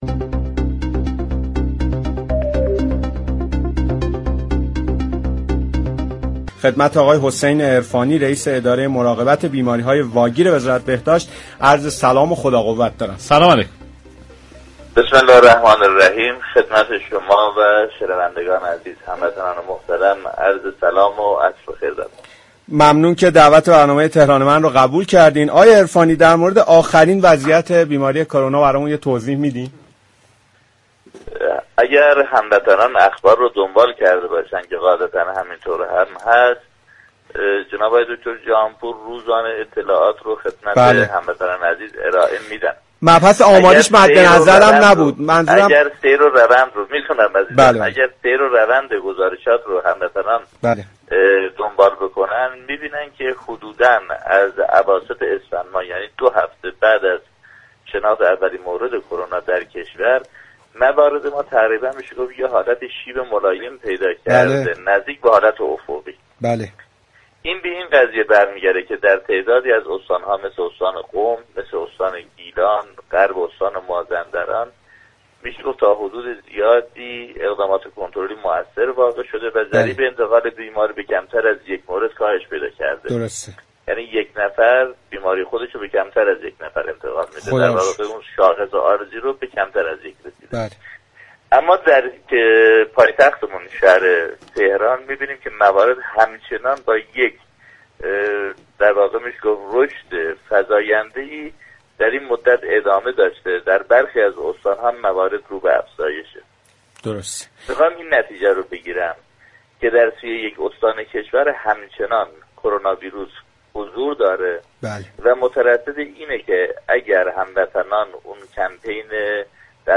مهمان تلفنی